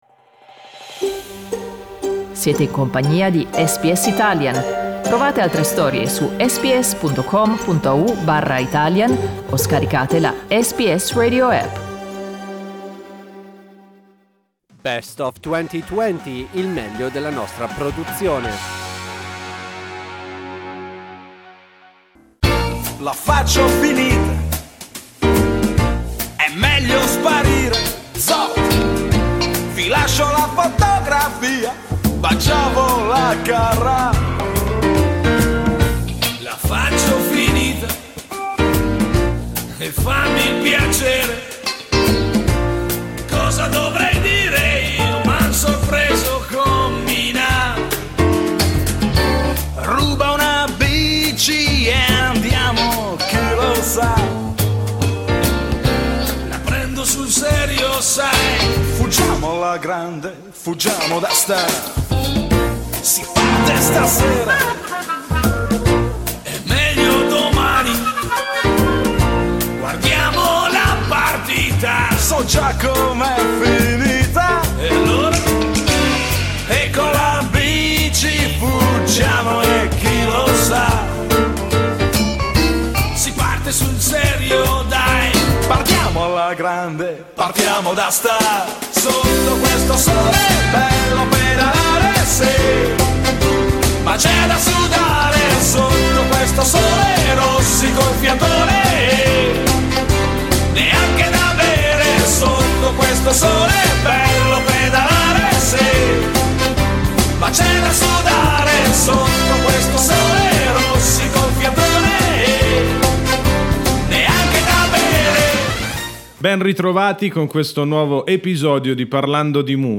Vi riproponiamo un'intervista con il musicista e showman emiliano, la cui carriera esplose nel 1990 con 'Sotto questo sole'.